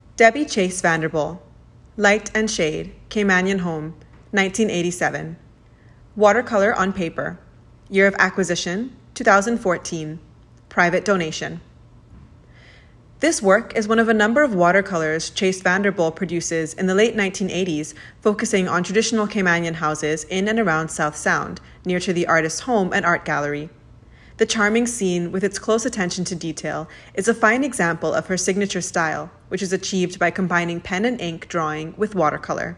Light and Shade Voiceover